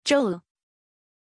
Pronunciation of Joel
pronunciation-joel-zh.mp3